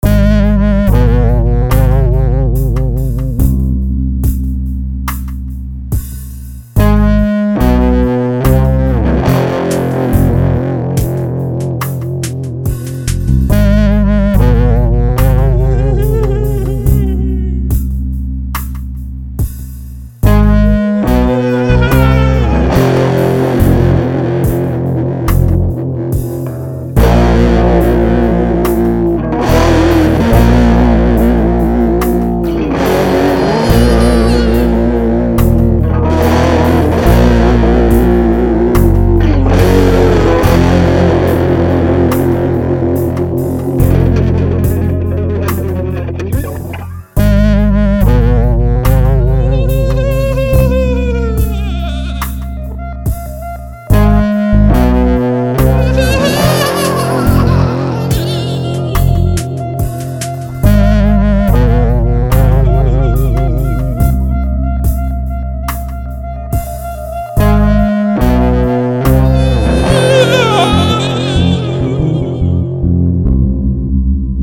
(Some kind of wailing & warbling)
A haunting, bowel movement-inducing number in the key of 'F'